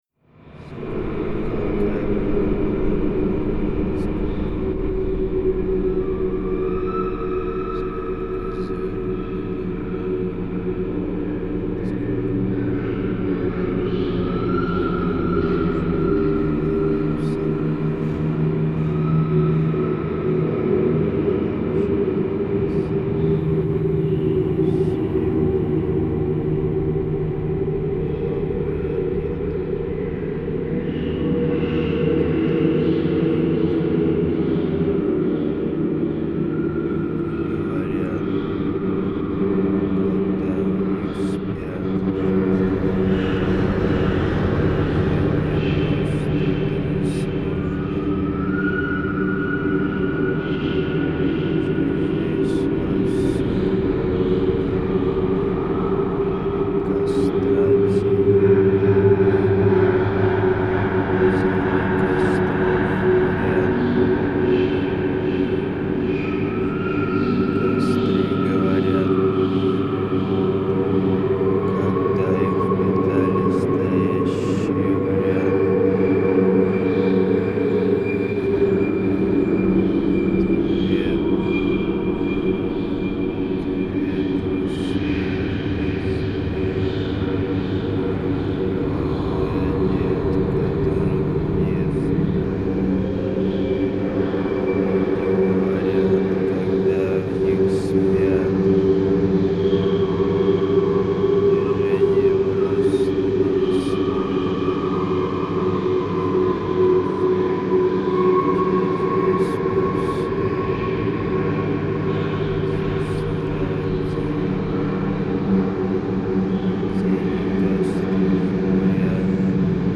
Genre: Dark Ambient, Drone.